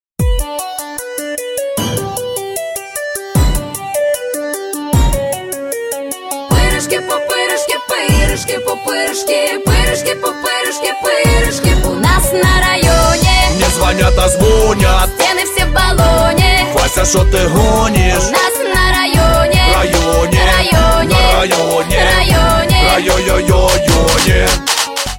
• Качество: 192, Stereo
поп
громкие
красивый женский голос